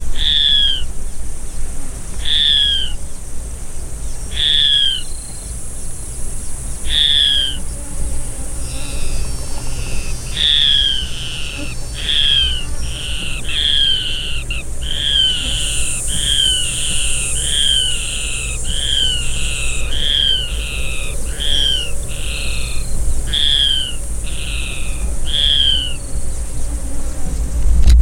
Ocellated Crake (Rufirallus schomburgkii)
Life Stage: Adult
Detailed location: Reserva Natural Silvestre Parque Federal Campo San Juan
Condition: Wild
Certainty: Recorded vocal
3 individuos vocalizando constantemente